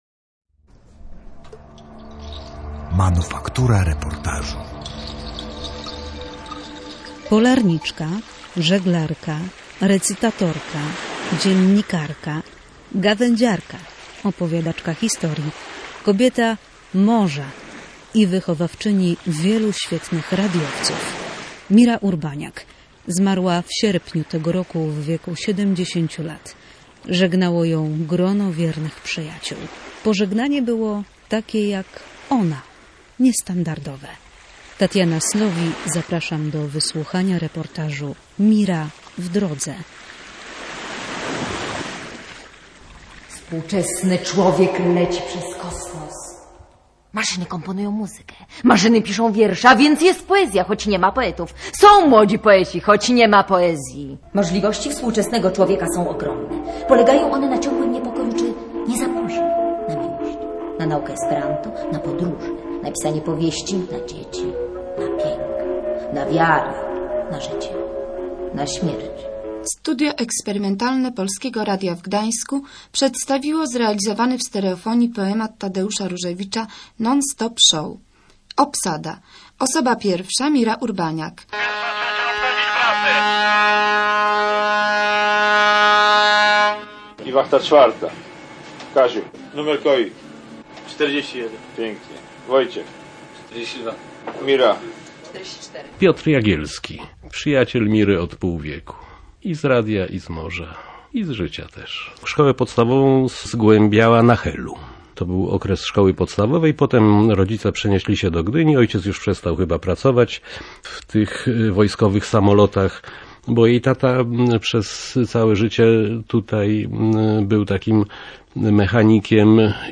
Reportaż
W dokumencie o dziennikarce wypowiadają się jej współpracownicy i przyjaciele.